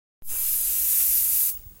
air freshener spray.ogg